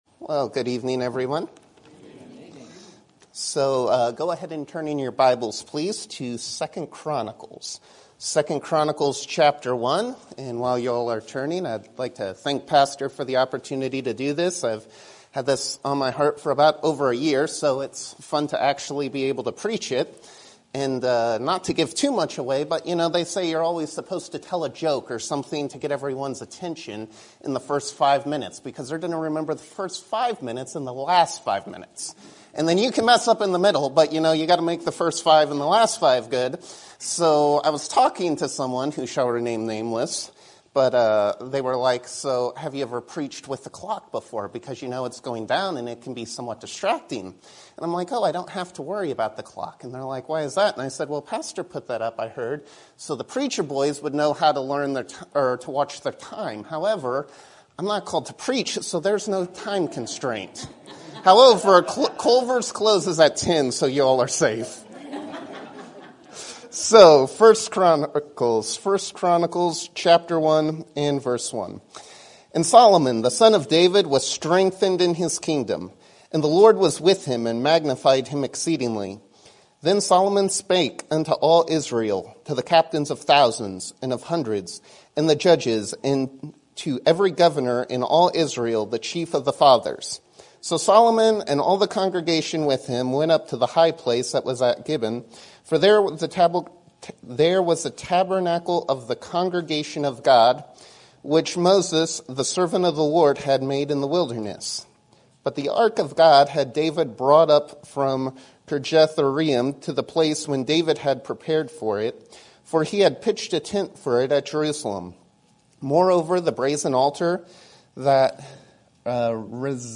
Sermon Topic: General Sermon Type: Service Sermon Audio: Sermon download: Download (20.39 MB) Sermon Tags: 2 Chronicles Solomon Knowledge Wisdom